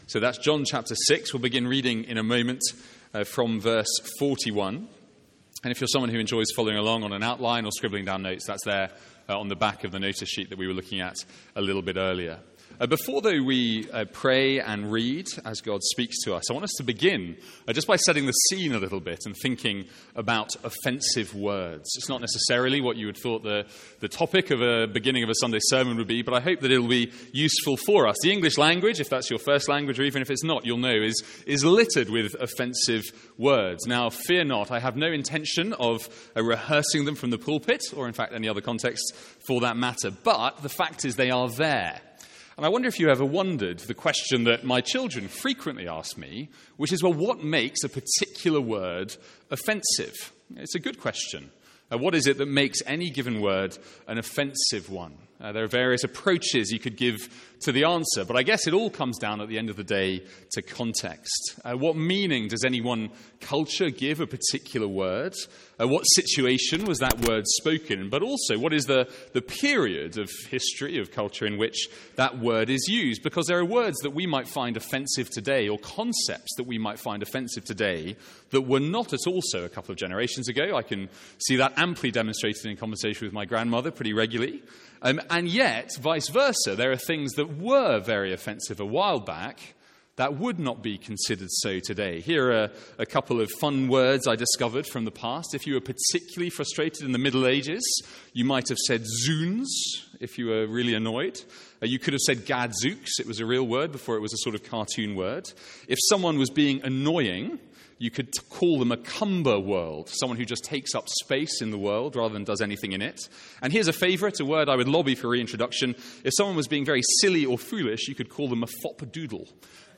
Sermons | St Andrews Free Church
From our morning series in John's Gospel.